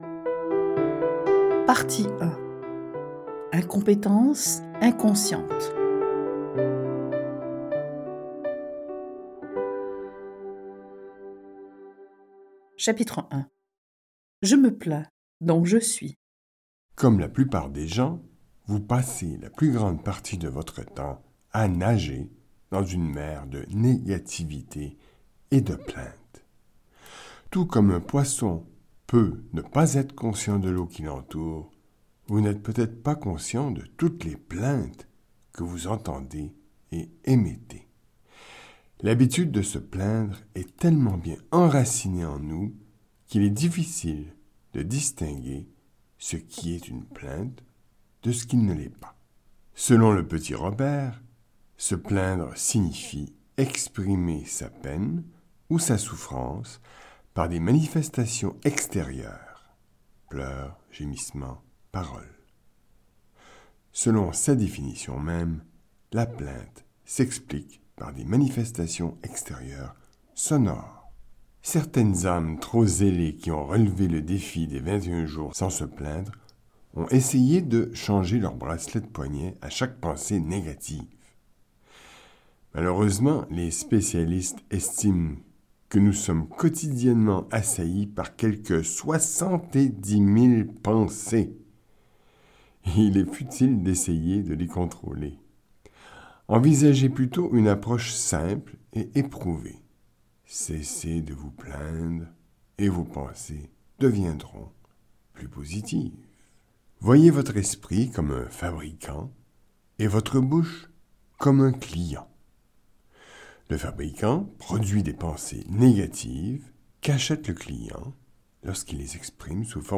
Dans ce livre audio, W...